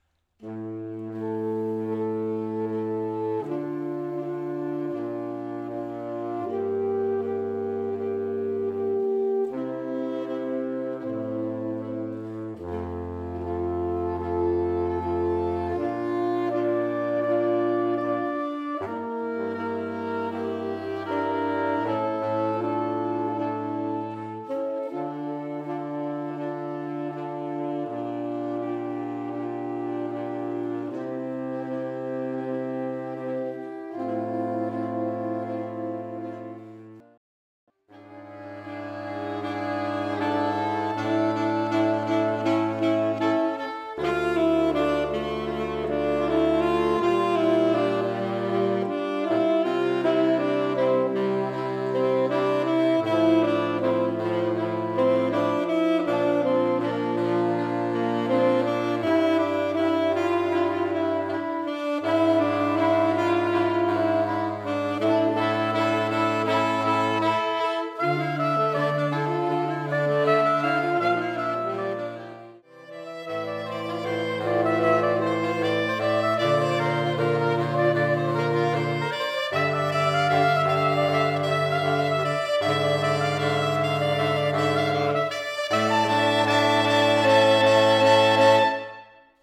Modernes